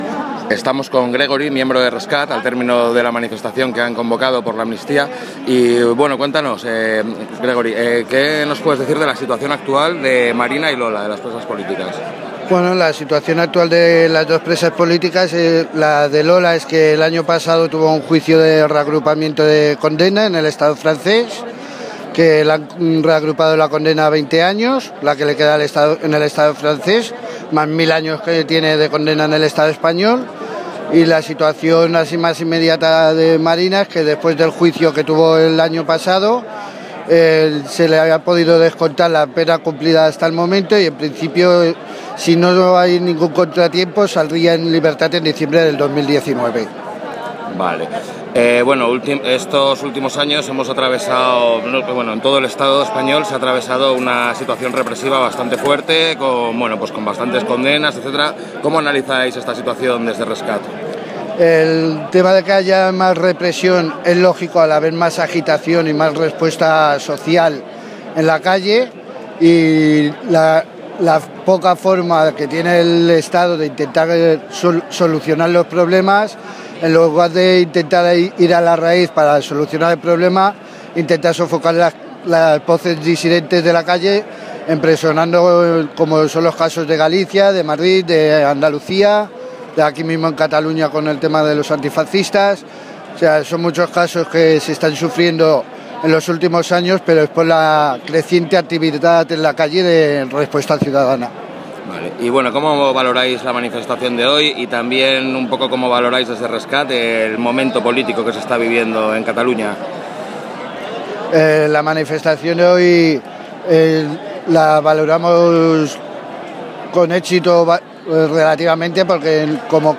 La Haine entrevista